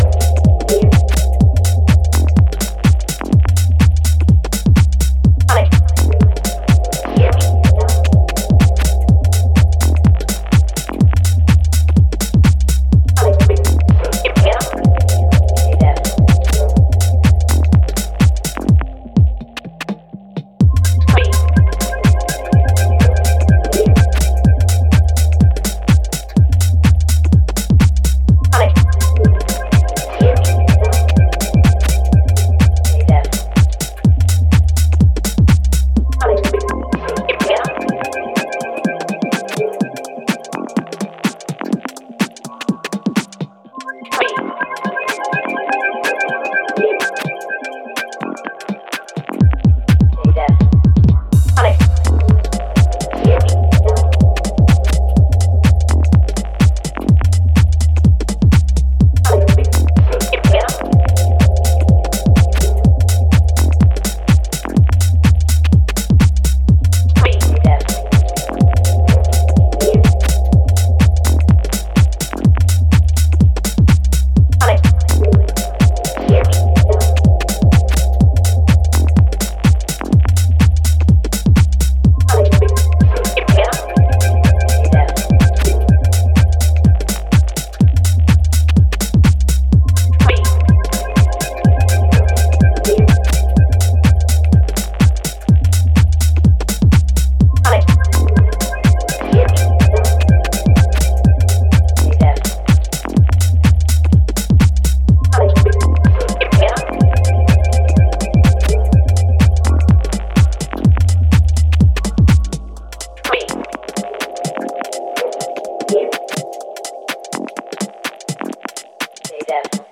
Inspired by the early white label house music releases.